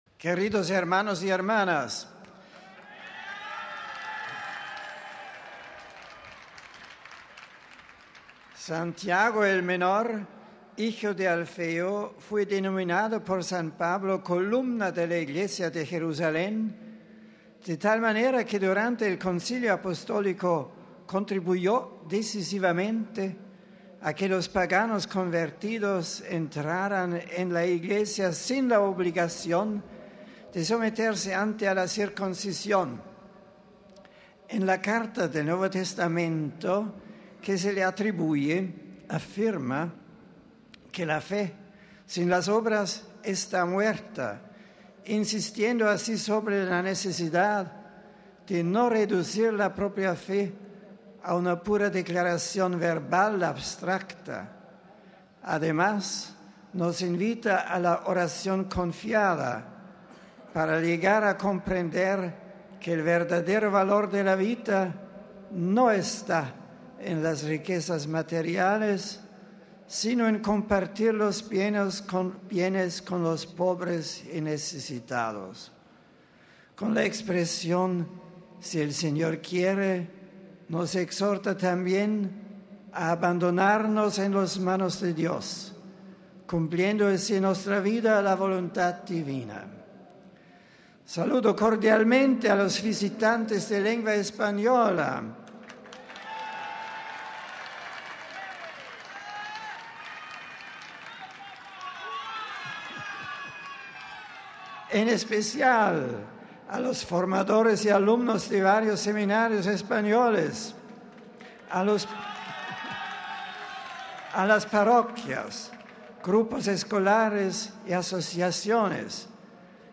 Durante la audiencia general, celebrada ante varios miles de personas en la Plaza de San Pedro en una jornada especialmente calurosa, el Papa ha dedicado su catequesis a la figura del apóstol Santiago el Menor, del que ha destacado "su intervención en la cuestión de la difícil relación entre los cristianos de origen judío y aquellos de origen pagano".
Este ha sido el resumen que de su catequesis ha hecho el Santo Padre en español para los peregrinos de nuestra lengua presentes en la Plaza de San Pedro: RealAudio